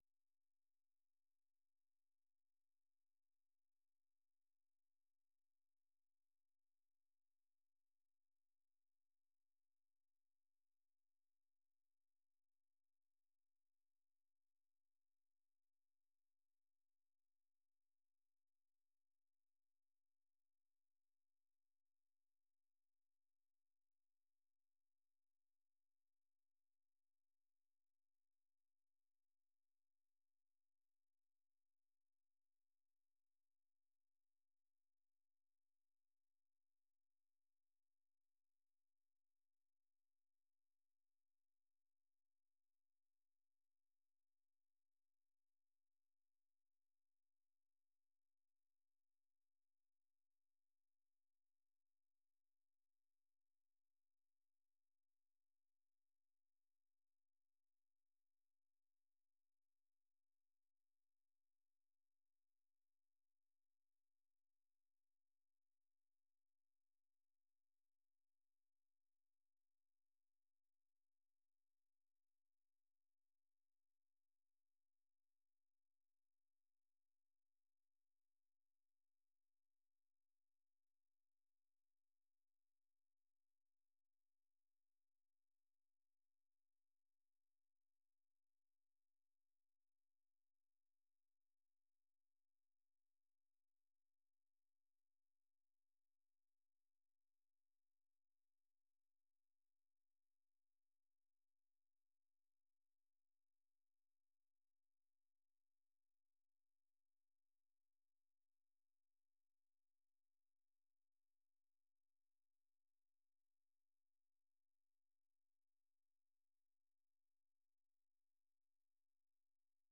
The URL has been copied to your clipboard 페이스북으로 공유하기 트위터로 공유하기 No media source currently available 0:00 0:59:58 0:00 생방송 여기는 워싱턴입니다 생방송 여기는 워싱턴입니다 저녁 공유 생방송 여기는 워싱턴입니다 저녁 share 세계 뉴스와 함께 미국의 모든 것을 소개하는 '생방송 여기는 워싱턴입니다', 저녁 방송입니다.